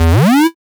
VEC3 Alarm FX
VEC3 FX Alarm 23.wav